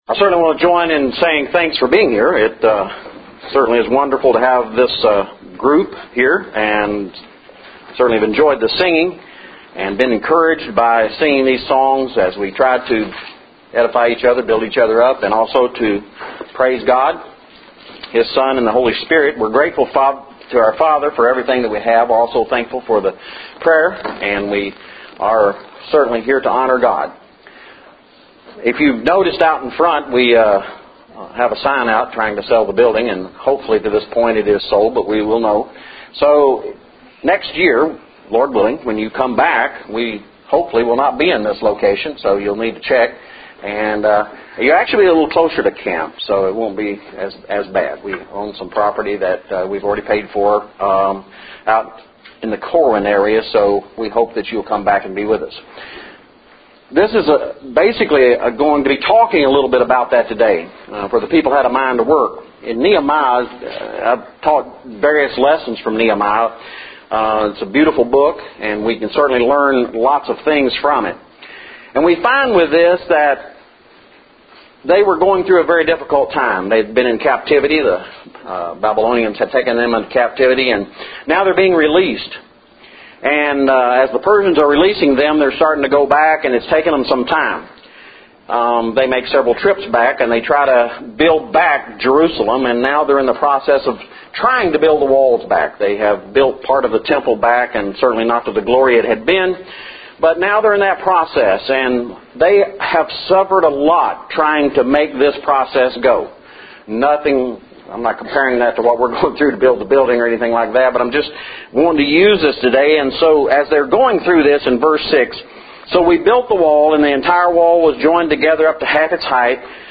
lessons